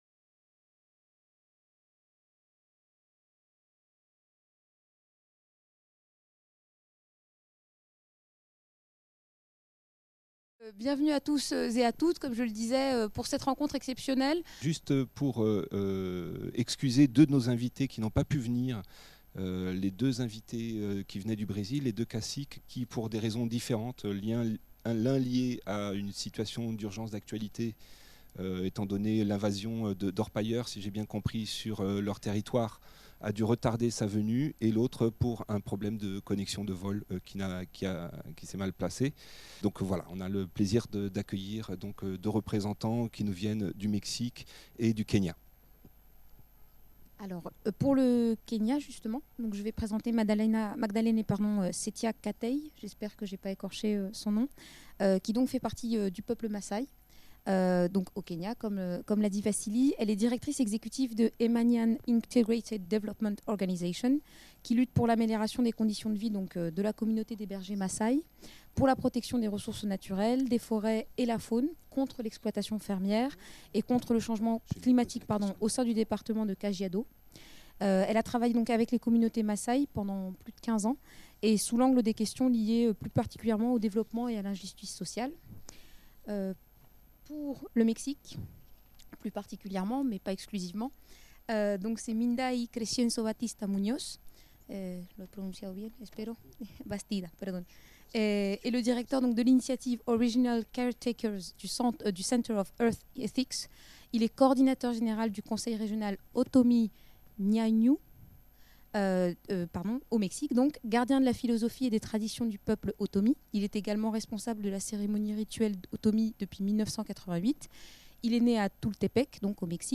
Sanctuarisation des forêts : rencontre - débat avec des leaders indigènes / gardiens de la nature | Canal U